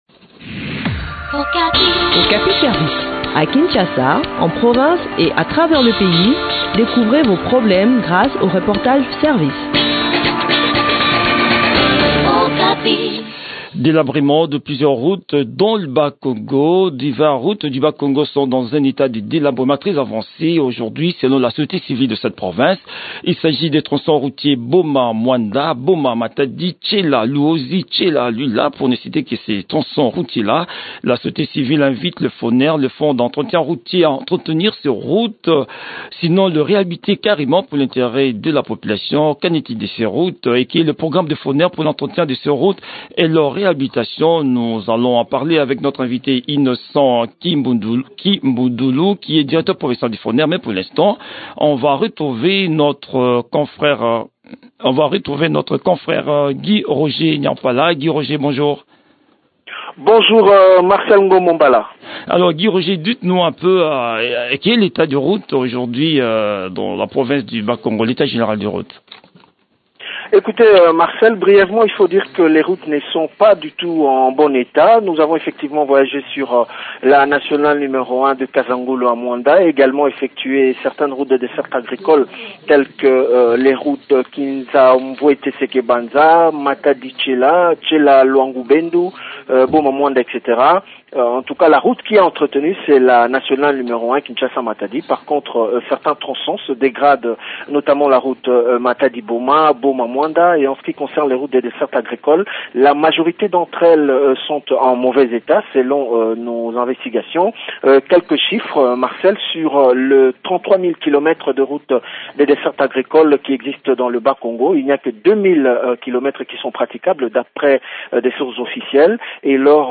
Le point de la situation sur le terrain dans cet entretien